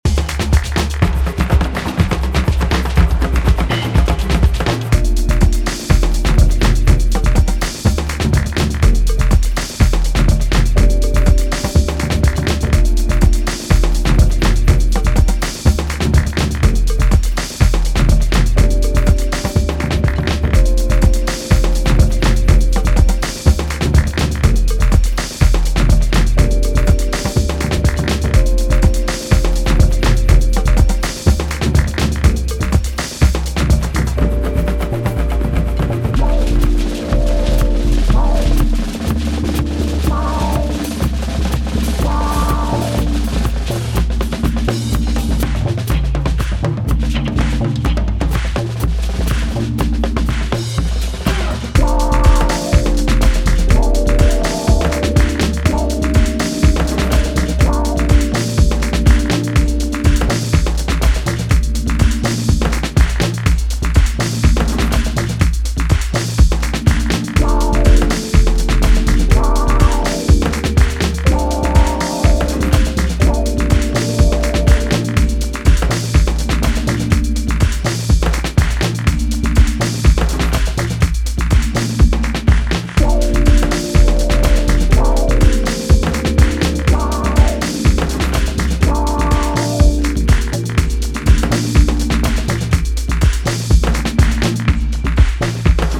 Urgency pours out of it.